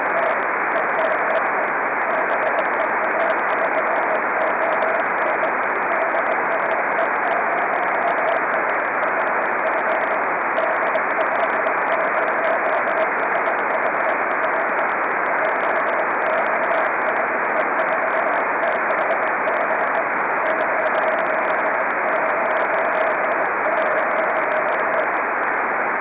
EME DL0SHF w paśmie 10.368,025 GHz
EME 3cm bikon